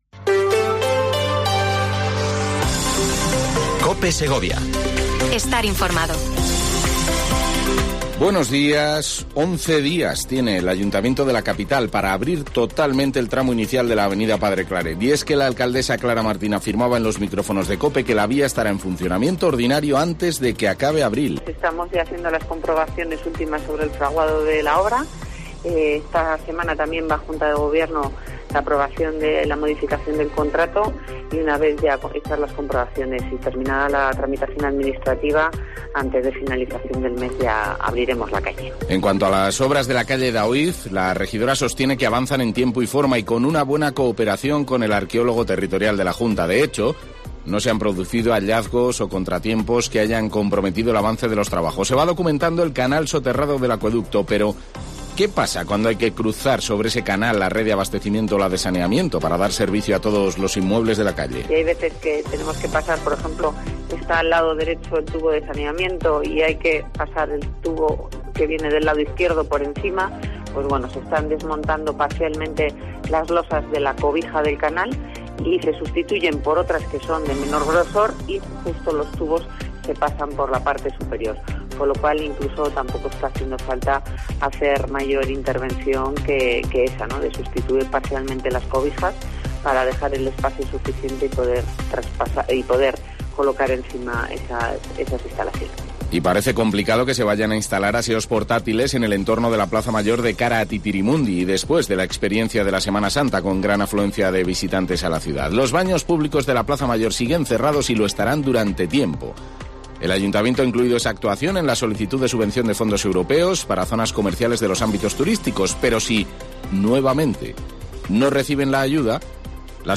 Informativo local Herrera en Cope Segovia, 8:24h. 19 de abril